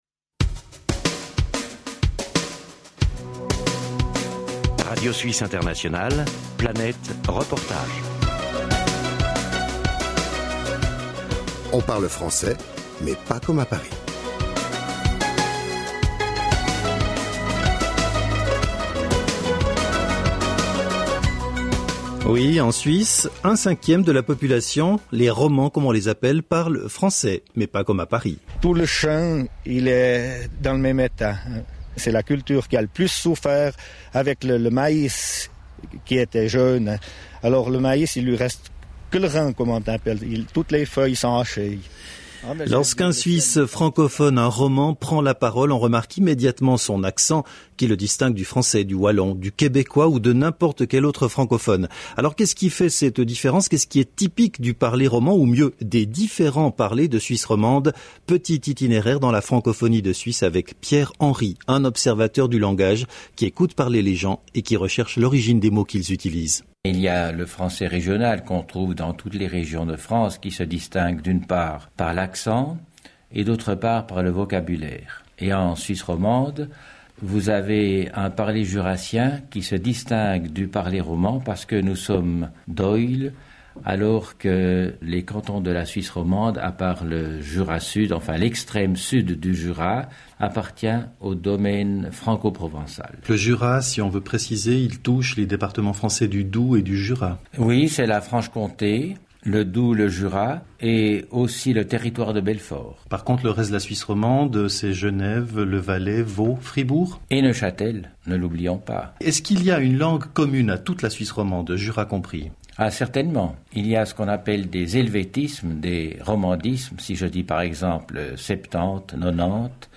(Archives Radio Suisse Internationale, 1997, série «Le français parlé»)